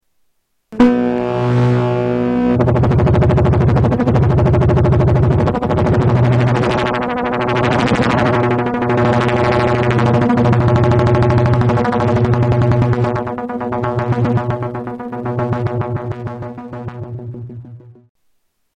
Tags: Sound Effects EML ElectroComp 101 EML101 ElectroComp 101 Synth Sounds